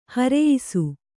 ♪ hareyisu